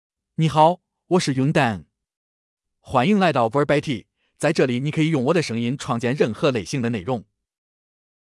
MaleChinese (Zhongyuan Mandarin Henan, Simplified)
Yundeng — Male Chinese AI voice
Yundeng is a male AI voice for Chinese (Zhongyuan Mandarin Henan, Simplified).
Voice sample
Listen to Yundeng's male Chinese voice.